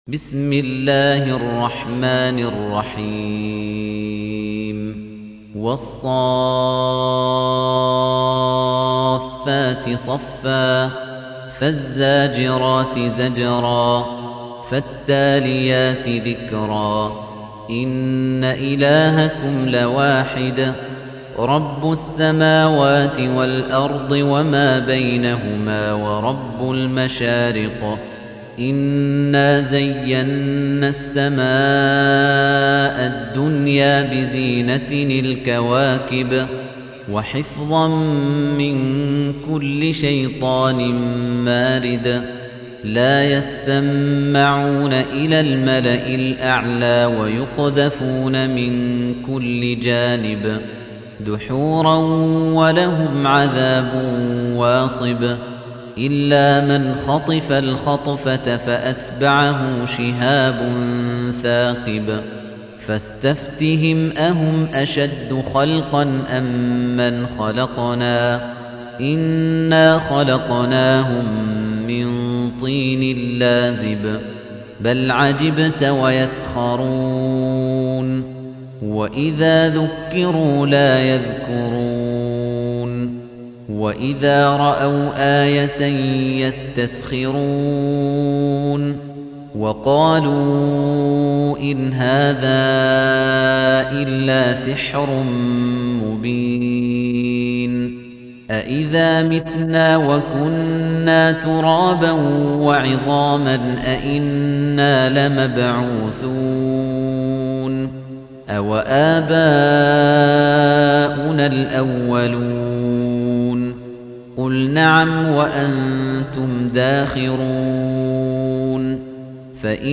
تحميل : 37. سورة الصافات / القارئ خليفة الطنيجي / القرآن الكريم / موقع يا حسين